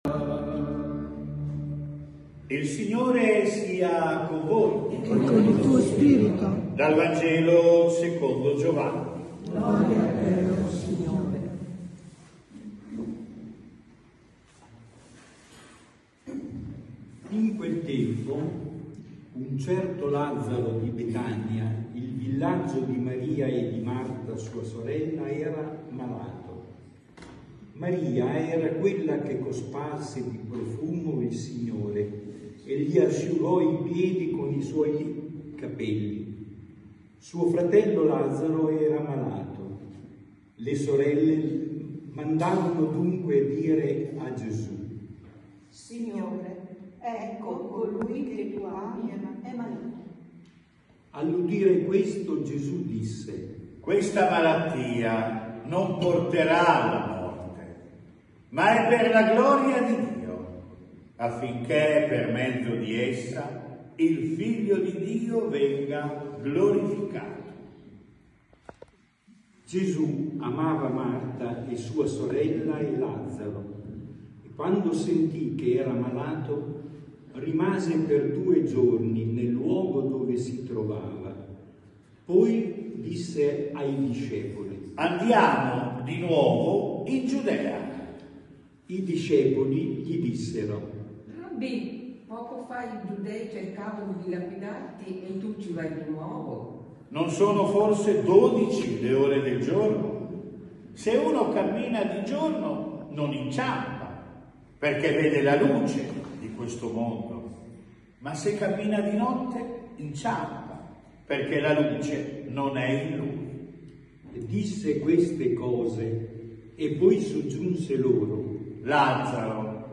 Omelia V quar. Anno A – Parrocchia San Pellegrino